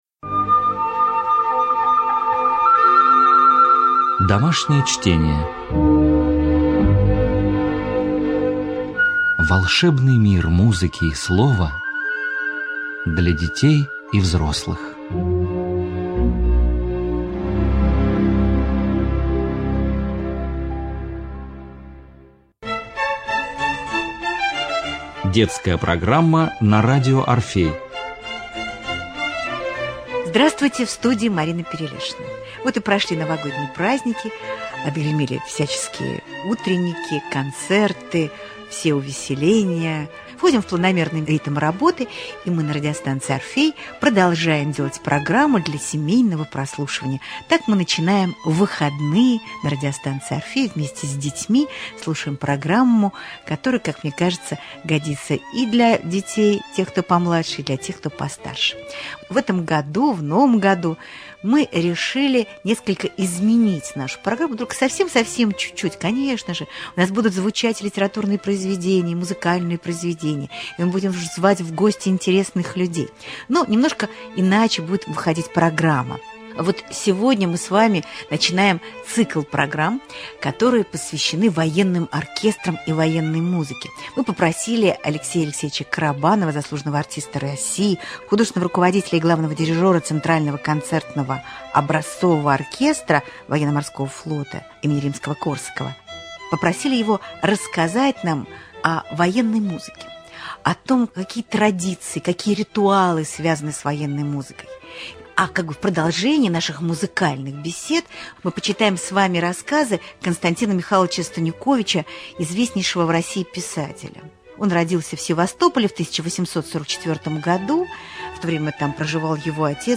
Цикл бесед о патриотической и военной музыке